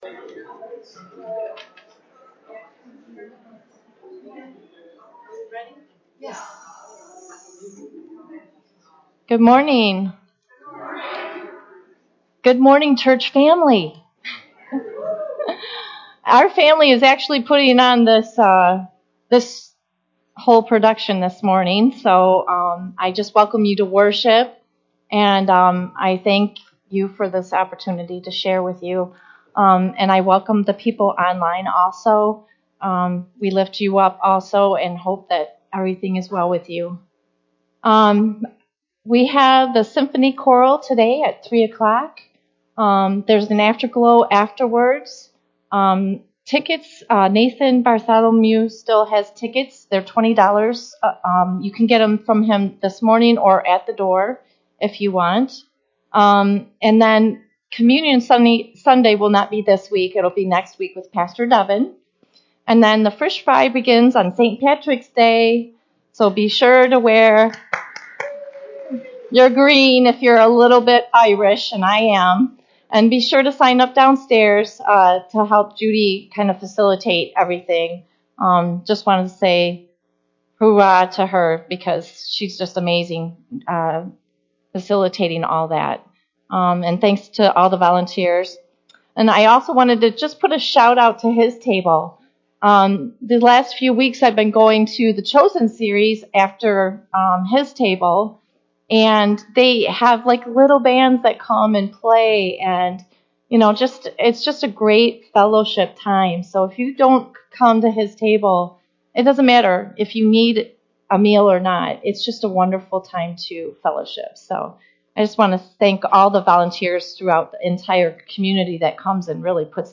RUMC-service-Mar-5-2023-CD.mp3